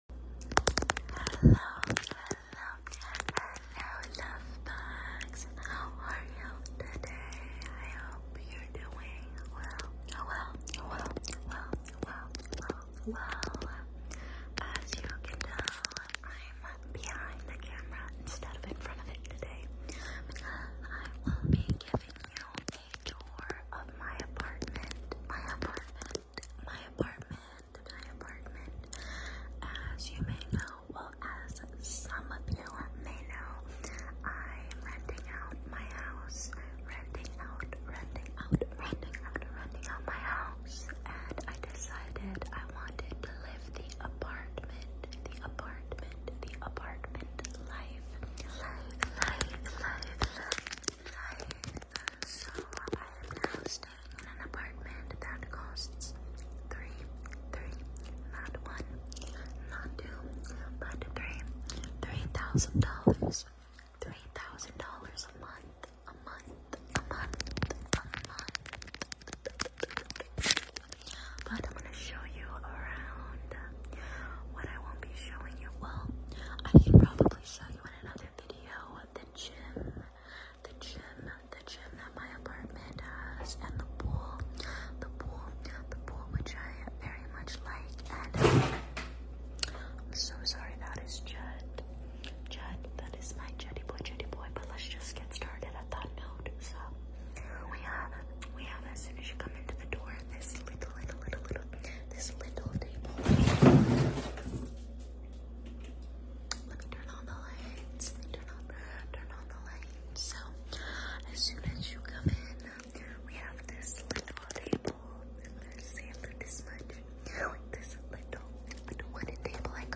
ASMR $3000 APARTMENT TOUR 🏠